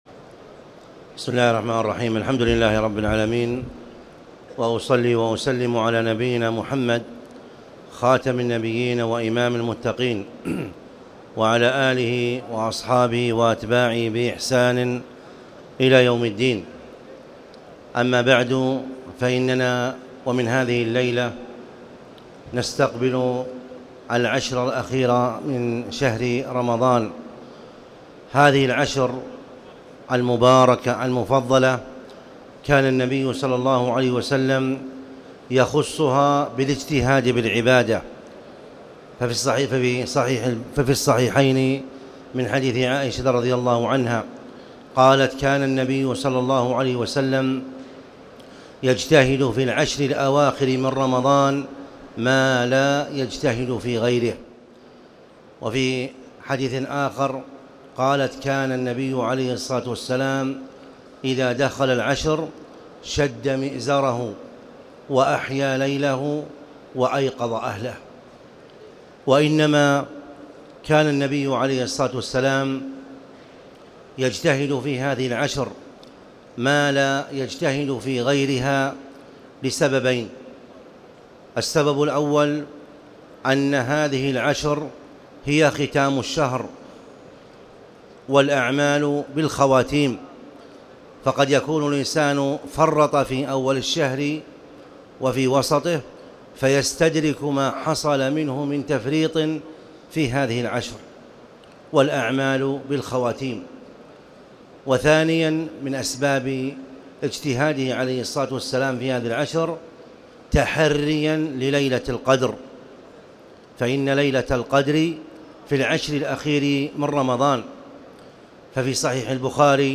تاريخ النشر ٢٠ رمضان ١٤٣٨ هـ المكان: المسجد الحرام الشيخ